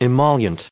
added pronounciation and merriam webster audio
270_emollient.ogg